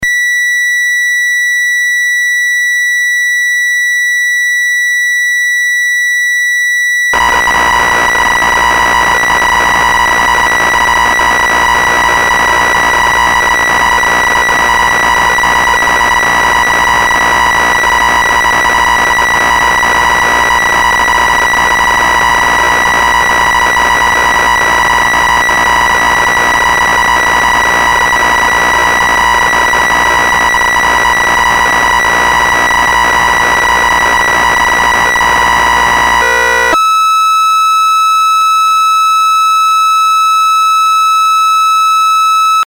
A digitally controlled analog subtracive synth with sliders. Similar to Sequential Six-Trak in sound no doubt but easier control of course.